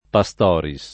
[ pa S t 0 ri S ]